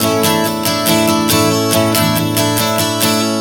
Strum 140 A 05.wav